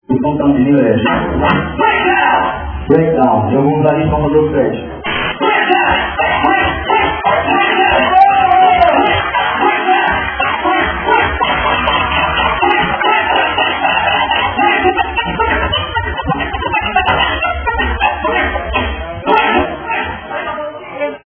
Scratch de voz
scratchvoz.mp3